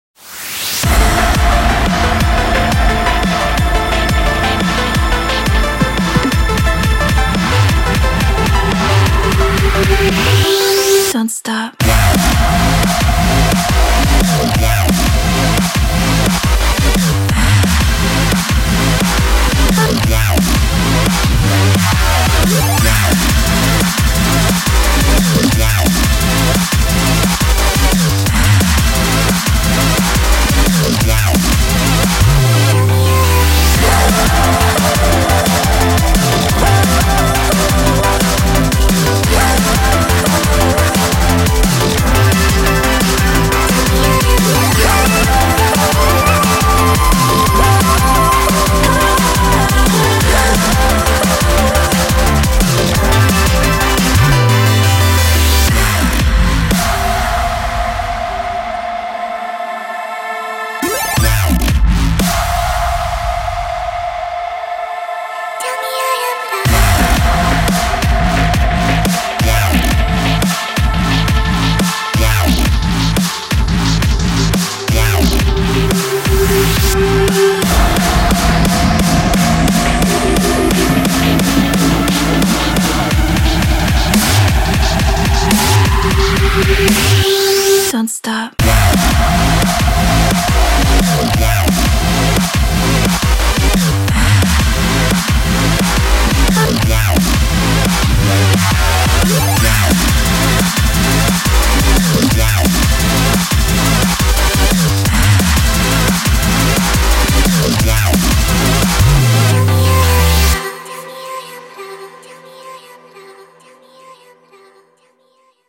BPM175
Audio QualityPerfect (High Quality)
[DRUMSTEP]